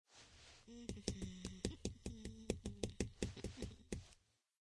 avatar_emotion_bored.ogg